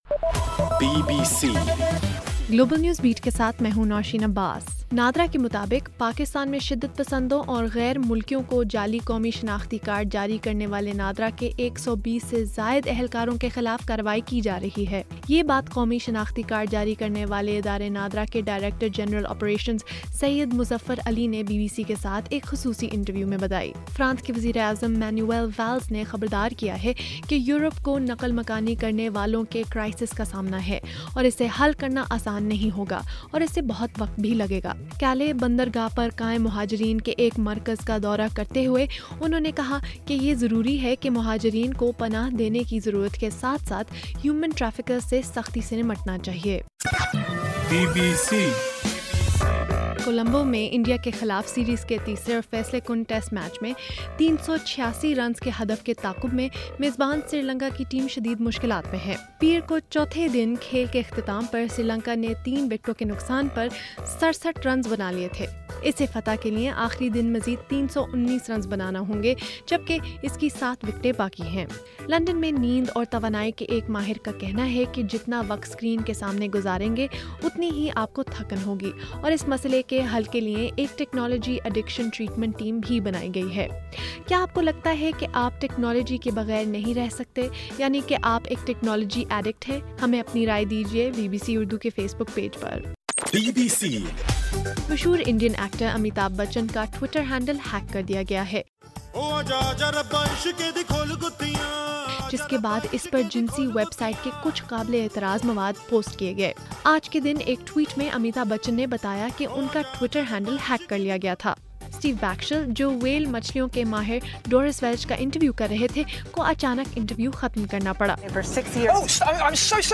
اگست 31: رات 10 بجے کا گلوبل نیوز بیٹ بُلیٹن